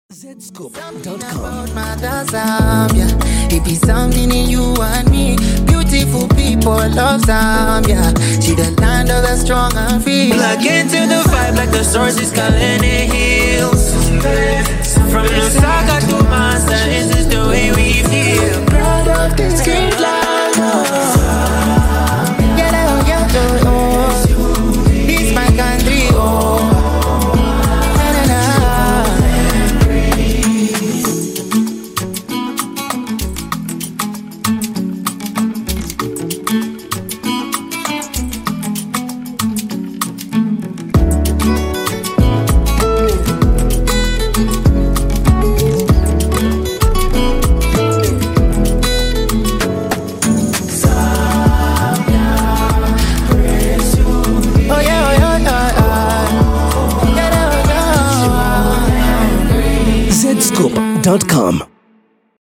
uses his sweet voice